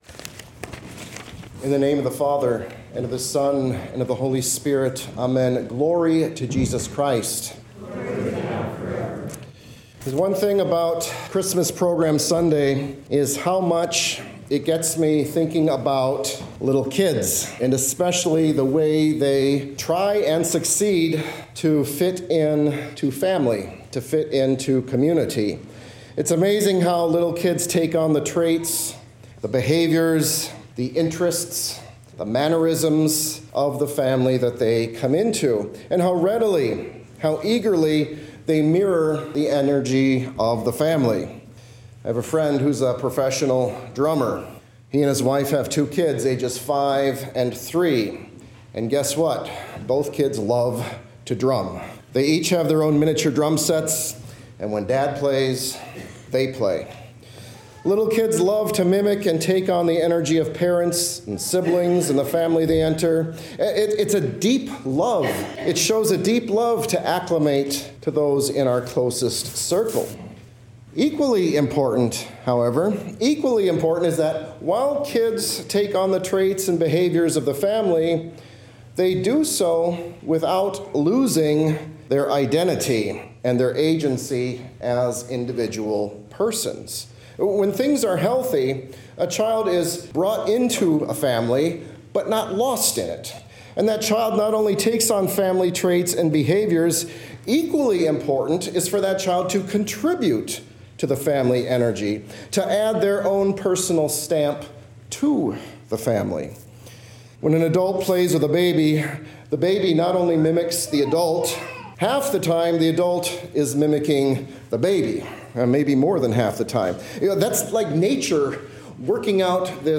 Sermons 2024